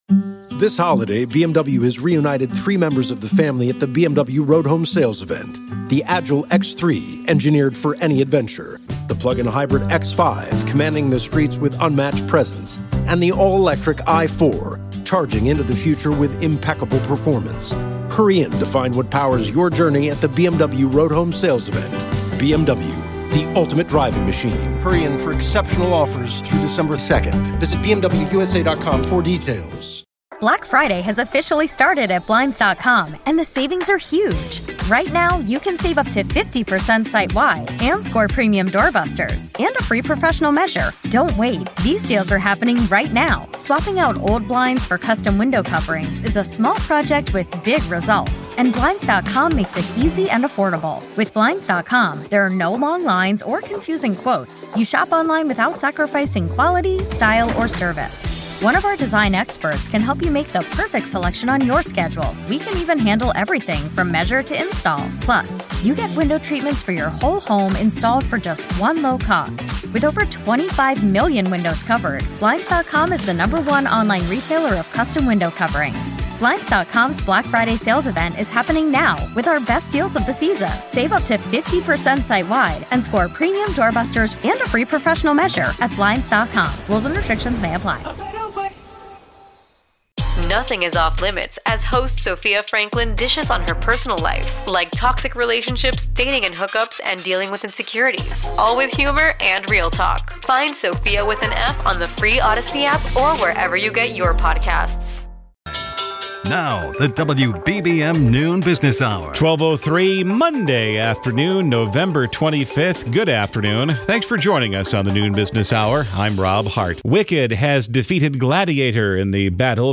WBBM devotes an hour each weekday to discussion about the economy and financial markets with some of the top experts in the nation.